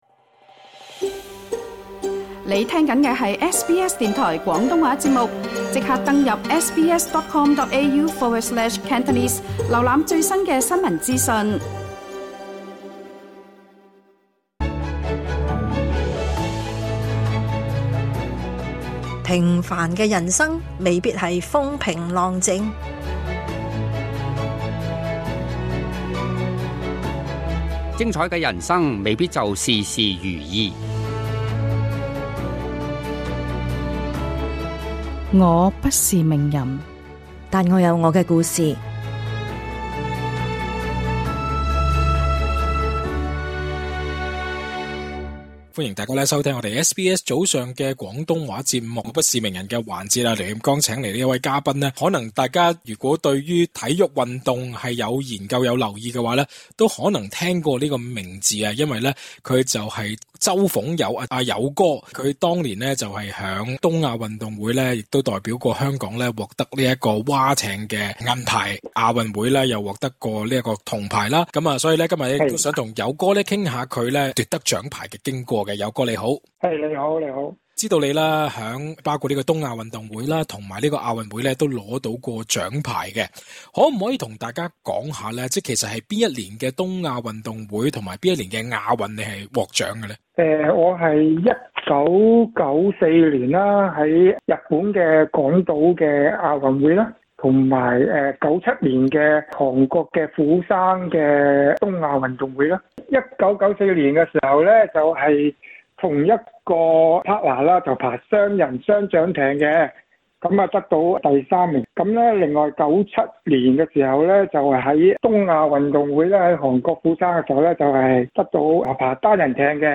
想了解更多詳請，請聽【我不是名人】的足本訪問。